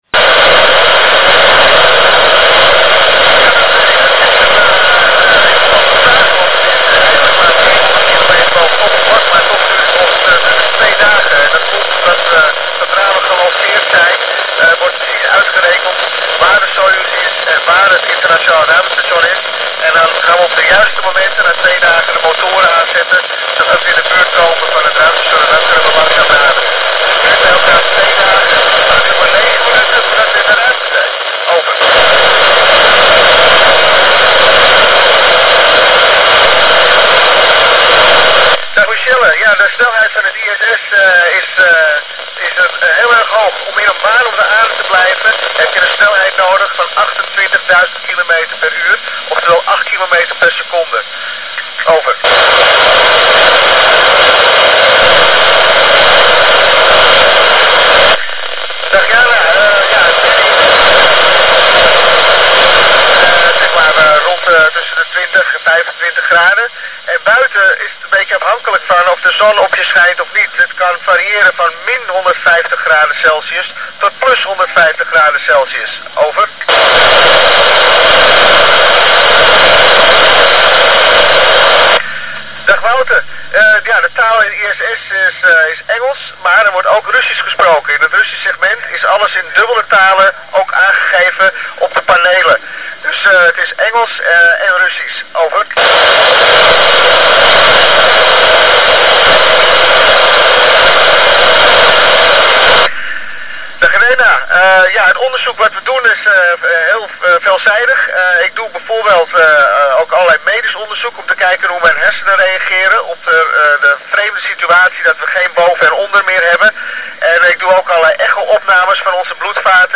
Andre Kuipers - ISS Exp. 30 - speaks with students at the Antheneum Borgloon via telebridge with K6DUE.
I have provided the entire recording from signal acquisition to signal loss using a handheld scanner with 1/4 wave mobile antenna and digital voice recorder. Nice pass, over seven minutes of audio received.